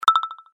короткие
электронные